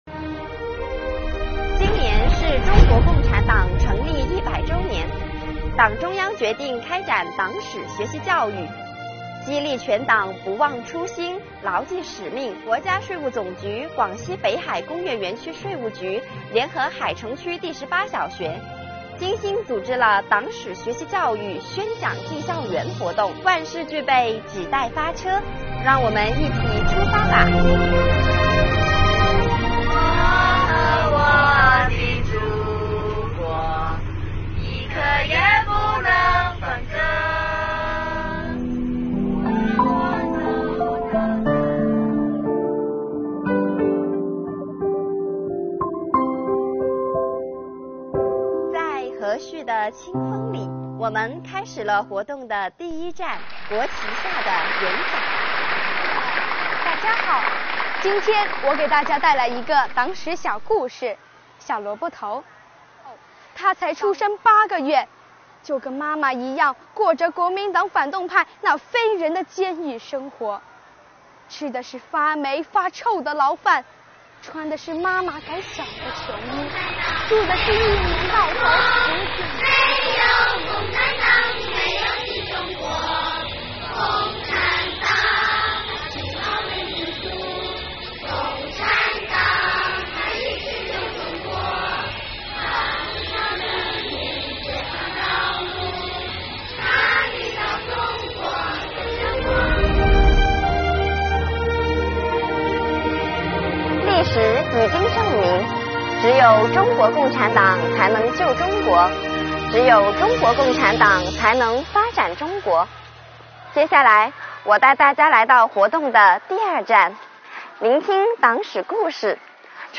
为深入开展好党史学习教育，国家税务总局广西北海工业园区税务局党员干部走进北海市海城区第十八小学，组织开展党史学习教育宣讲进校园主题活动。